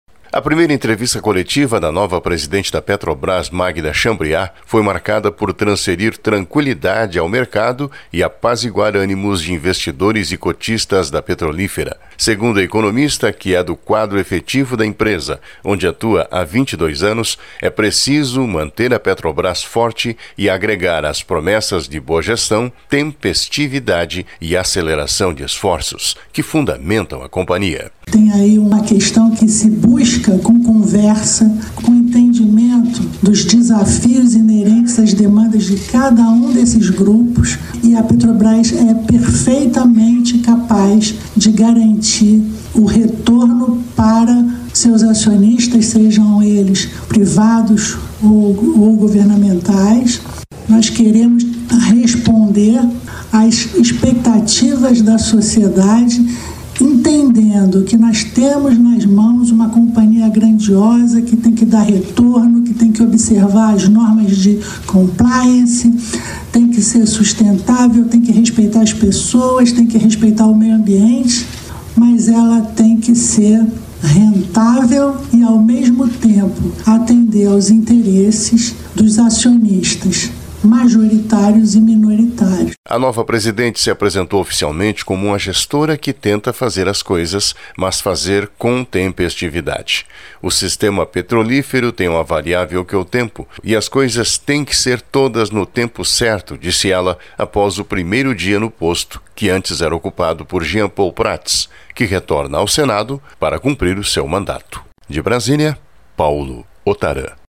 Magda Chambriard diz em primeira coletiva de imprensa que é preciso manter os valores da Petrobrás
Magda-Chambriard-diz-em-primeira-coletiva-de-imprensa-que-e-preciso-manter-os-valores-da-Petrobras.mp3